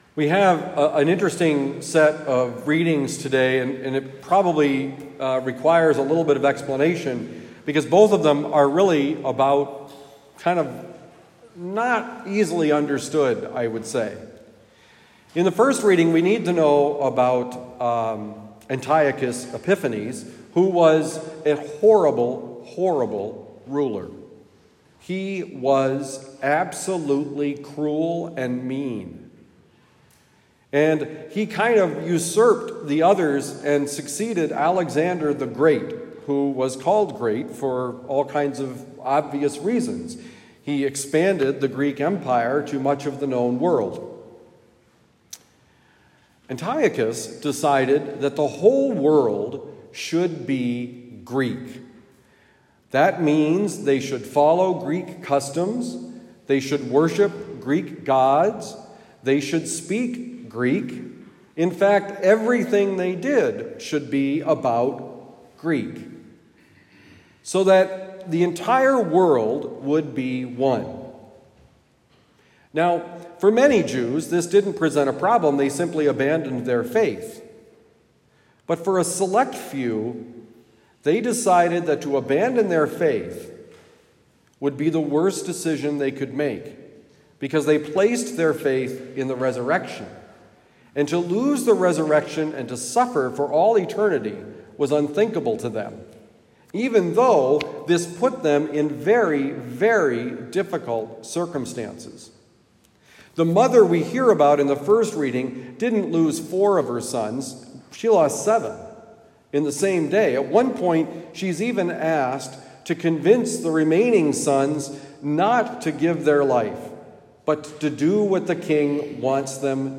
Homily given at Our Lady of Lourdes Parish, University City, Missouri.